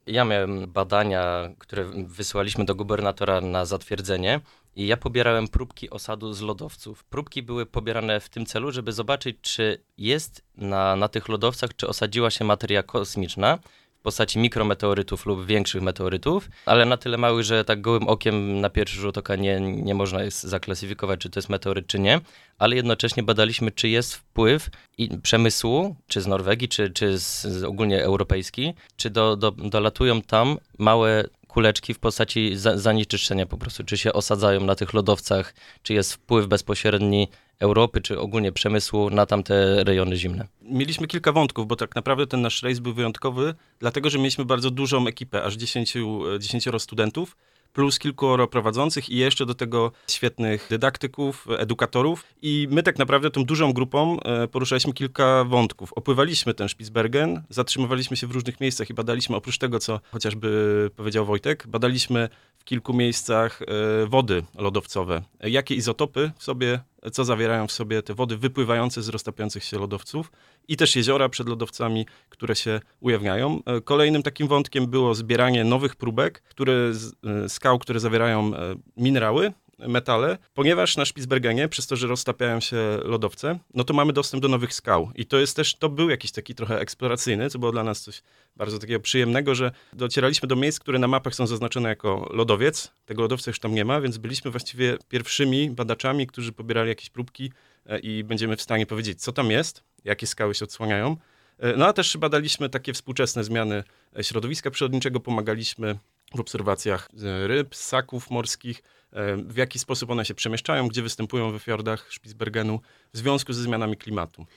W studiu Radia Rodzina